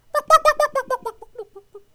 chicken_die3.wav